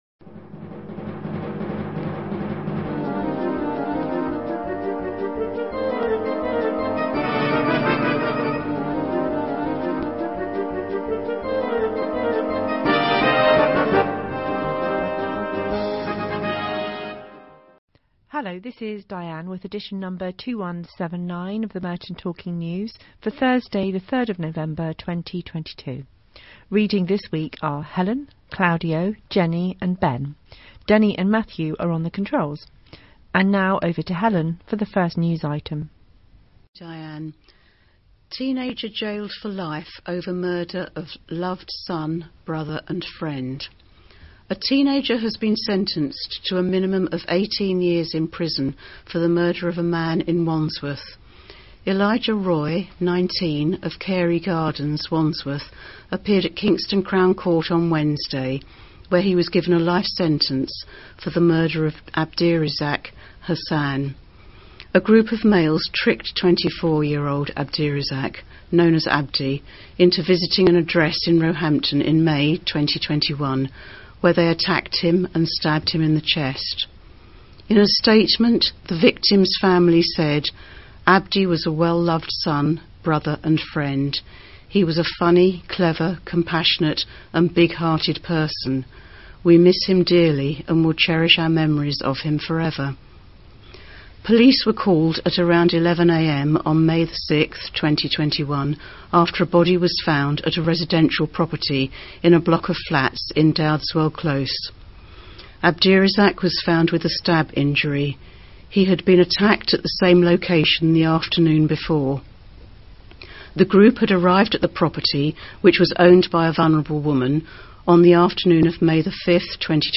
Latest edition: 3 November 2022 Download Audio Newspaper (9mb) Download Audio Magazine (7mb) If you’d like to receive a copy each week on cassette or a USB drive why not look at subscribing .